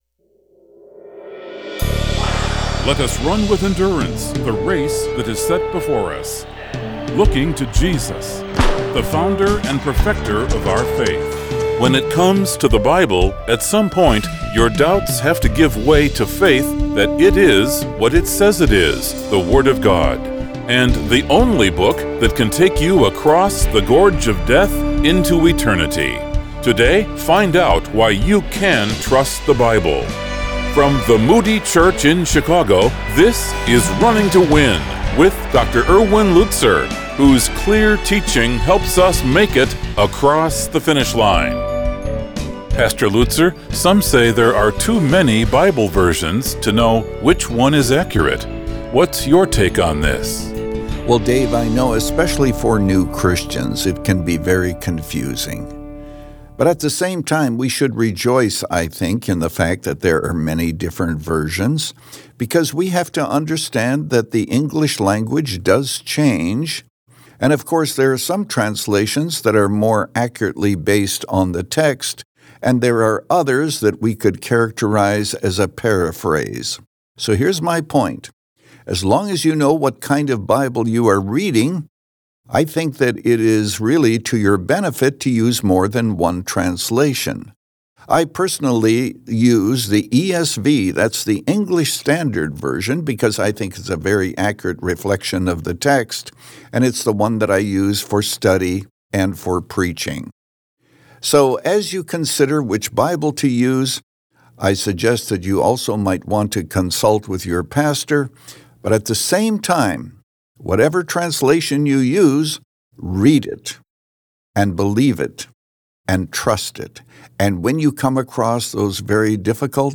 But with the Bible front and center and a heart to encourage, Pastor Erwin Lutzer presents clear Bible teaching, helping you make it across the finish line. Since 2011, this 25-minute program has provided a Godward focus and features listeners’ questions.